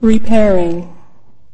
Original C&C "Repairing…" voice audio file.